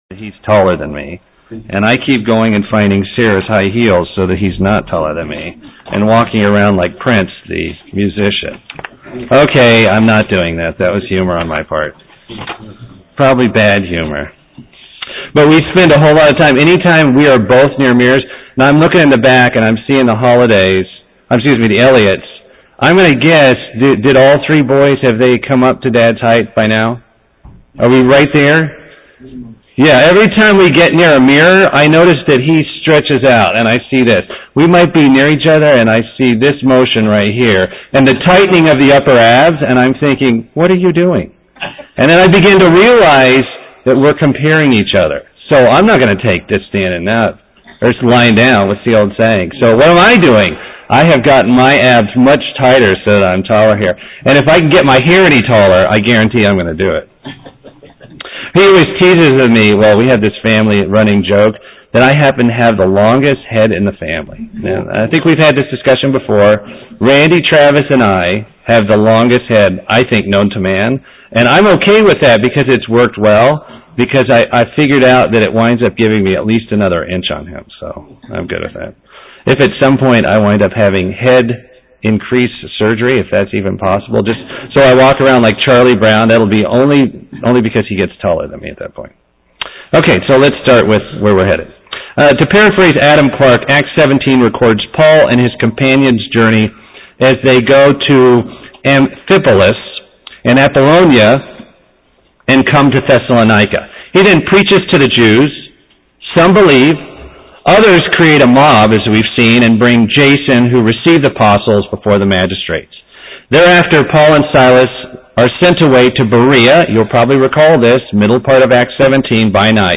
A continuation of a Bible study on the book of Acts with a exploration of Acts 18.
UCG Sermon Studying the bible?
Given in Buford, GA